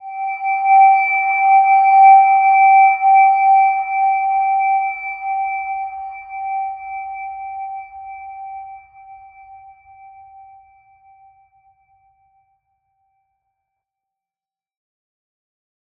Silver-Gem-G5-mf.wav